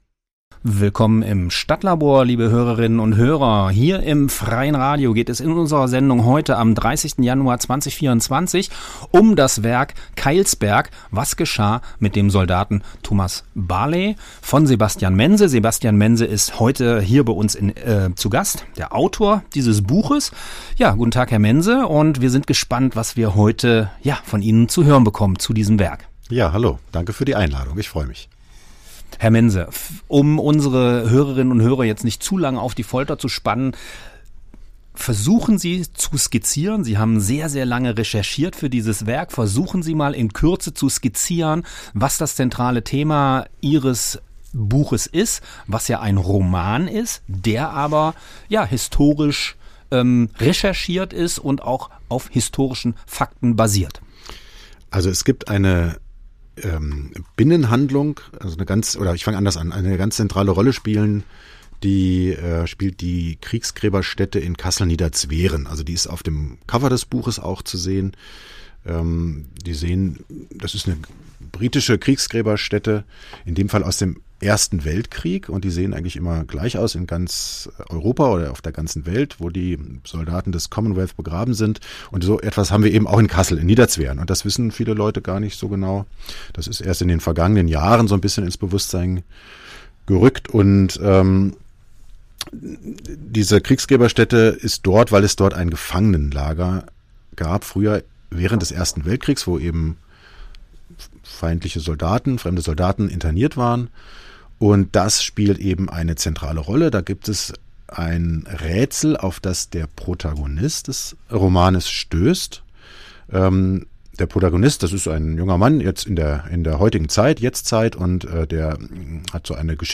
Das StadtLabor wurde am 30.1.2025 im Freien Radio Kassel gesendet. Die Musiktitel sind im Podcast nicht enthalten.